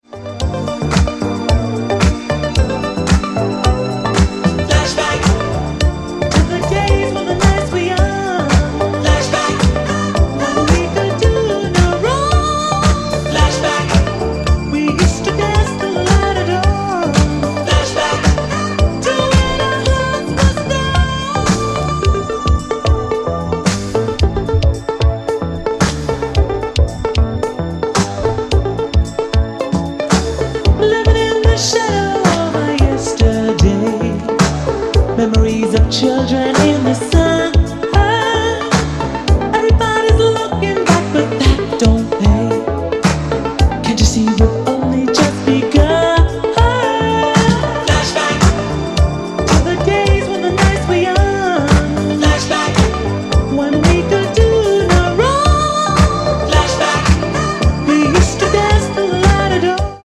More 80s boogie goodies.
Boogie. Disco. Electro